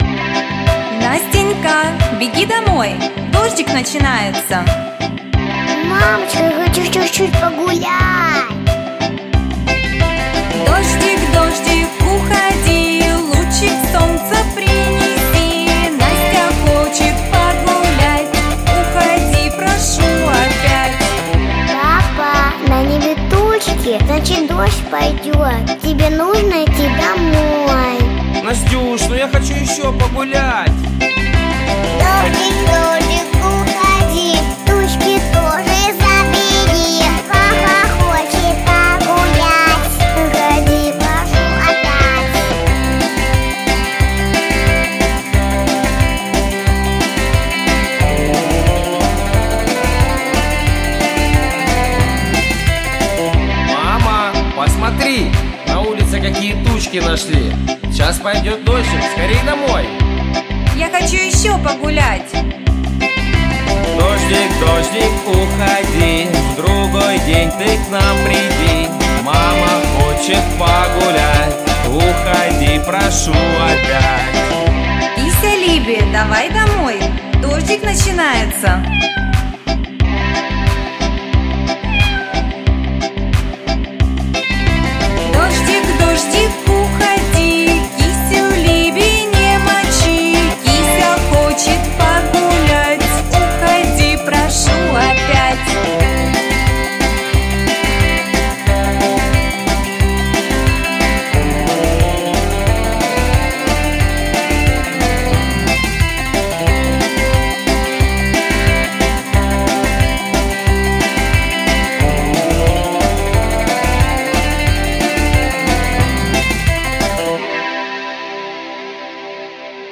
• Качество: Хорошее
• Жанр: Детские песни
малышковые